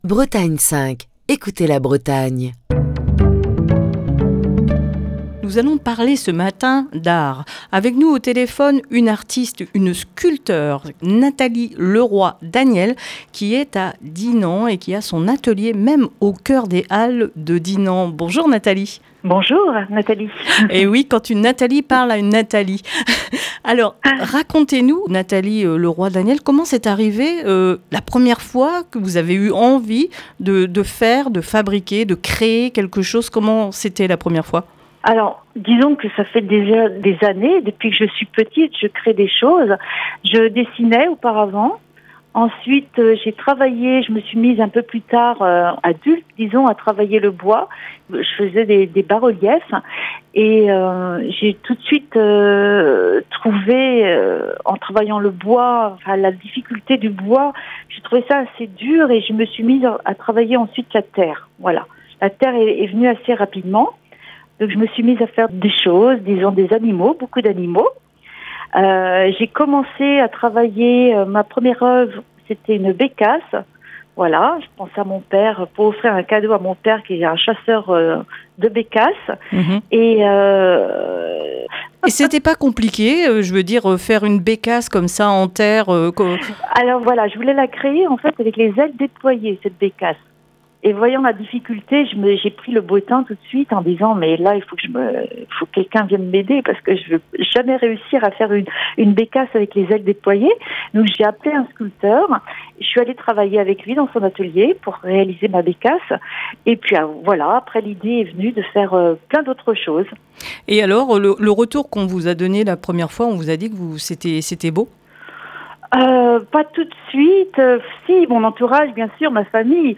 passe un coup de fil